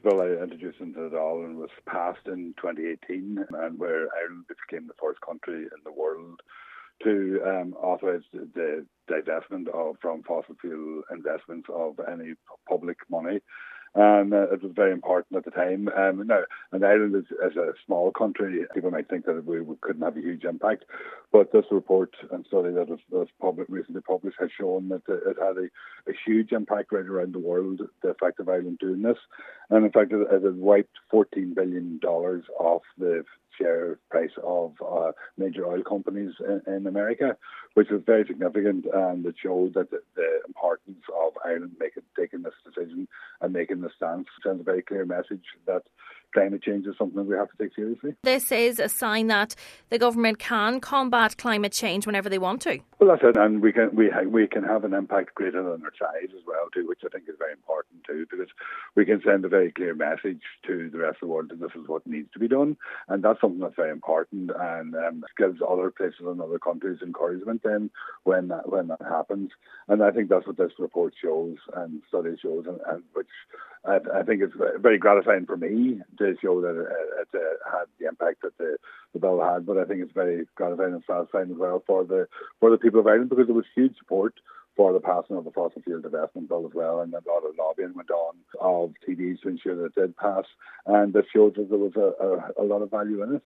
Deputy Pringle says it shows Ireland leading the way: